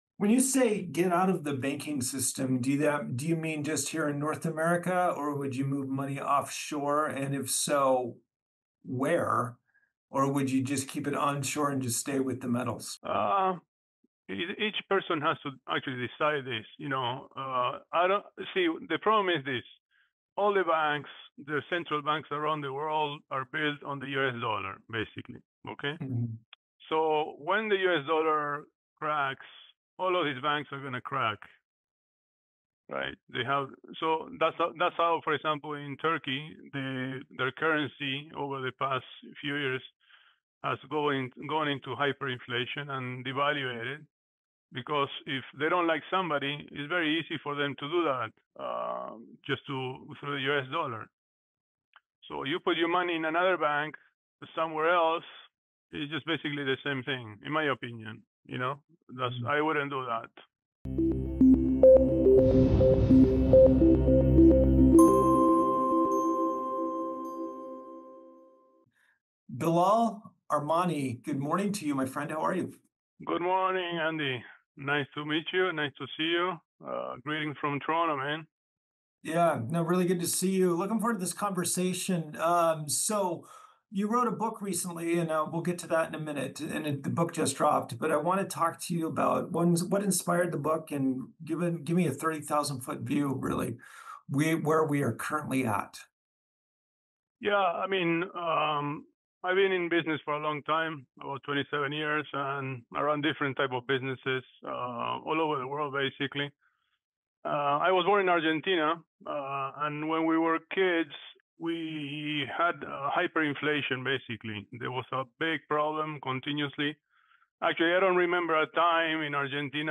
In this eye-opening interview